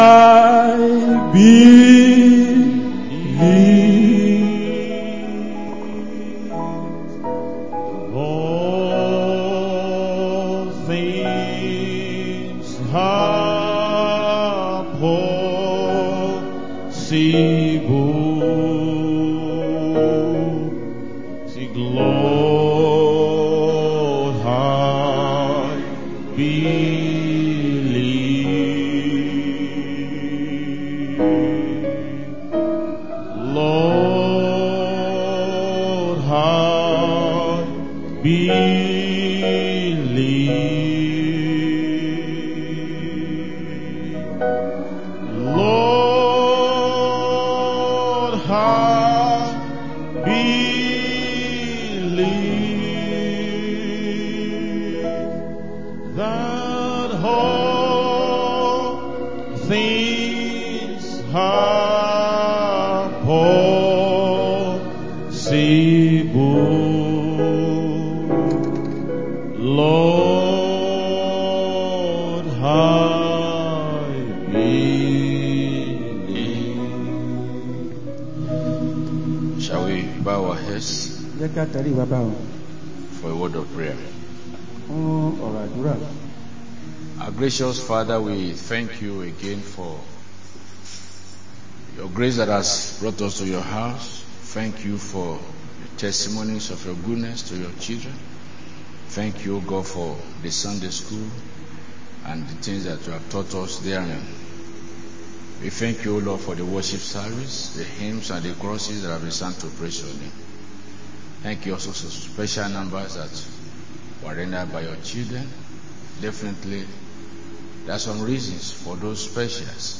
Sunday Main Service 26-10-25